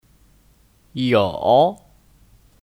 有 Yǒu (Kata kerja): Punya, Ada